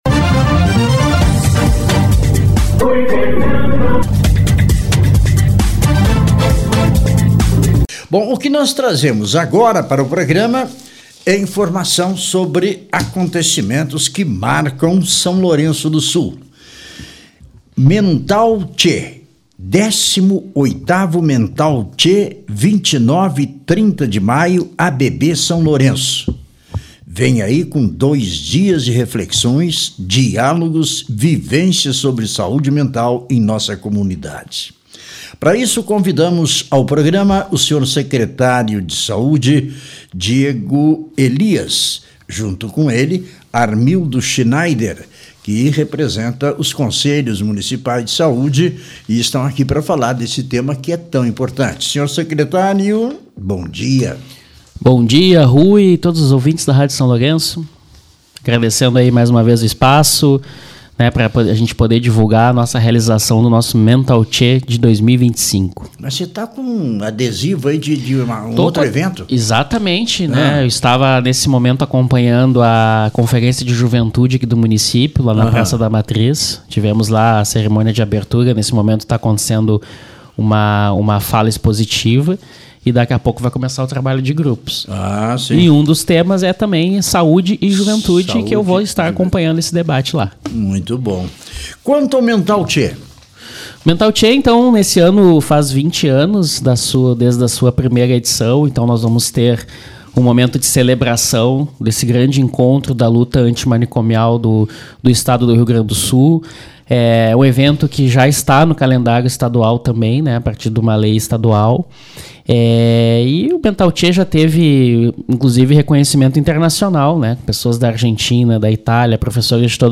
Entrevista com o Secretário de Saúde